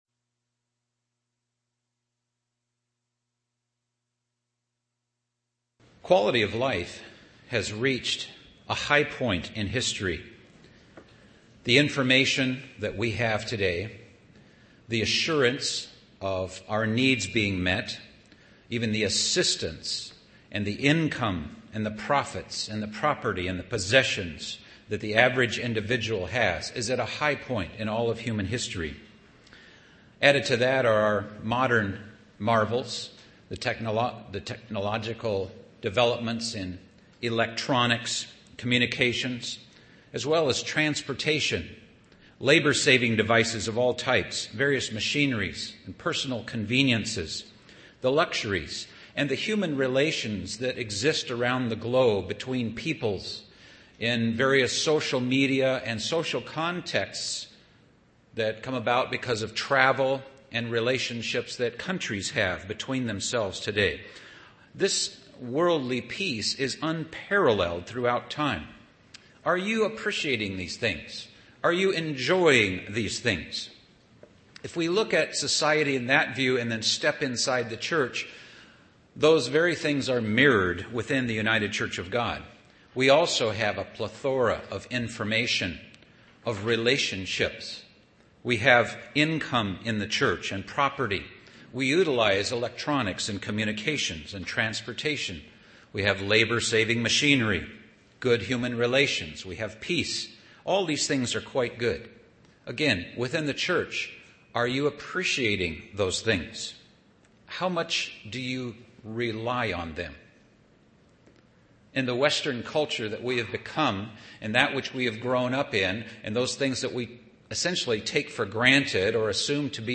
This 3-part sermon series provides an overview of what is coming, and how to be preparing for it.